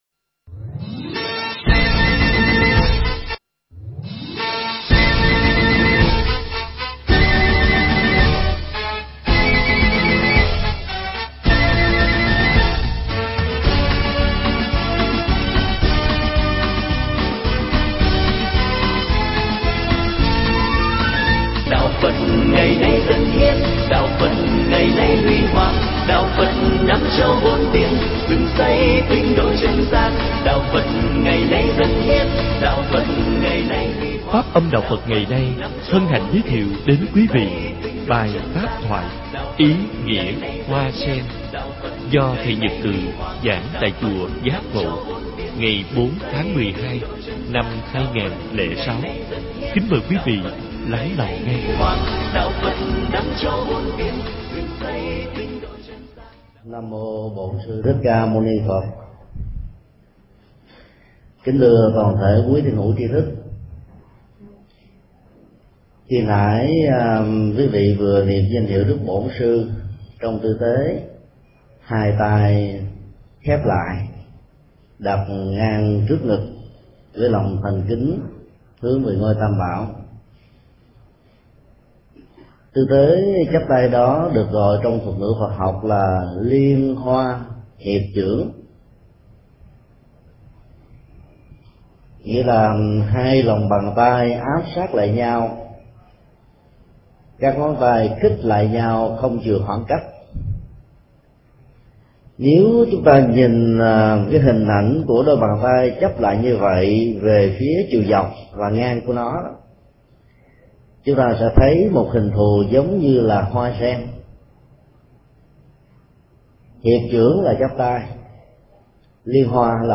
Nghe mp3 thuyết pháp Ý nghĩa hoa sen do thượng toạ Thích Nhật Từ giảng tại Chùa Giác Ngộ, ngày 4 tháng 12 năm 2006.